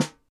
Drum Samples
S n a r e s